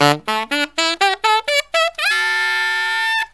63SAXFALL1-R.wav